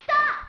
Mr. Time's "Stop!" voice clip from Tetris Attack.